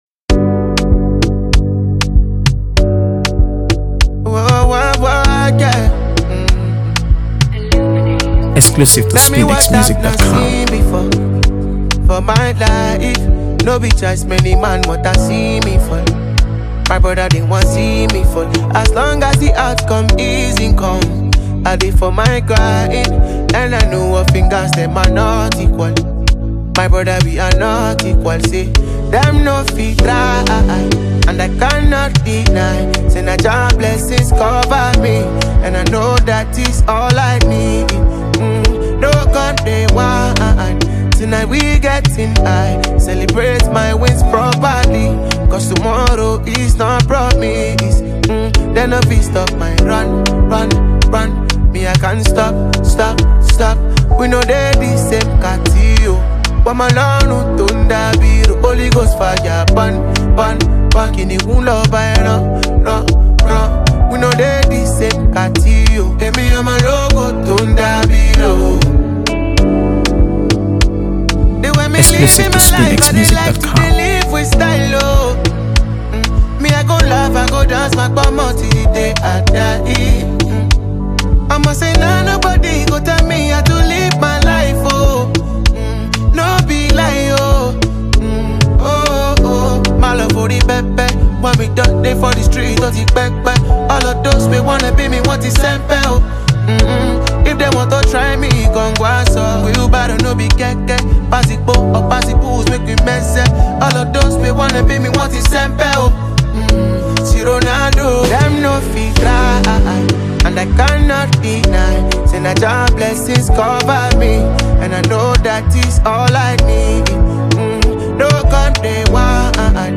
AfroBeats | AfroBeats songs
fuses Afrobeats with soulful pop